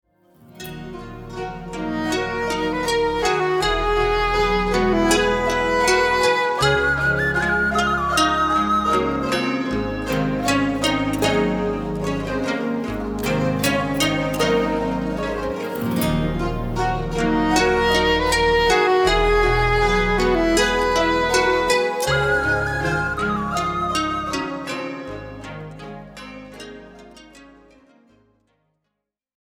re-mastered, re.engineered sound, bonus songs, bonus videos